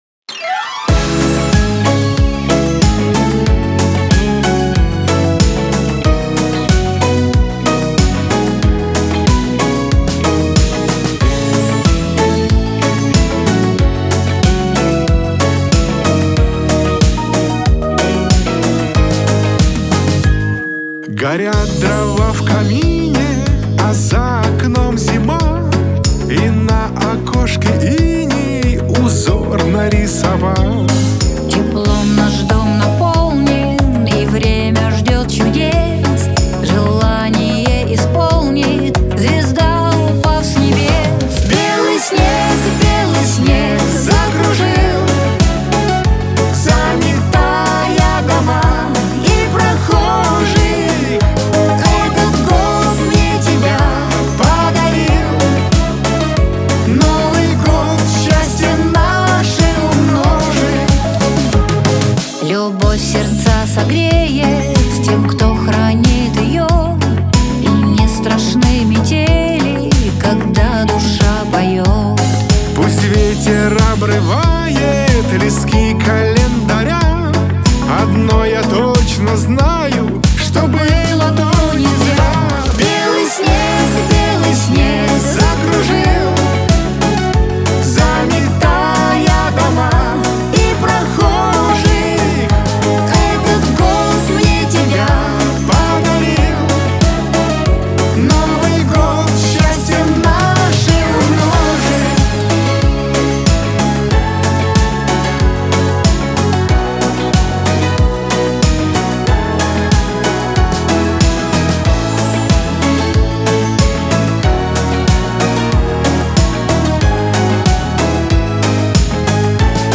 диско , эстрада
дуэт